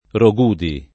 vai all'elenco alfabetico delle voci ingrandisci il carattere 100% rimpicciolisci il carattere stampa invia tramite posta elettronica codividi su Facebook Roghudi [ ro g2 di ] top. (Cal.) — pn. loc. (greca) ro K2D i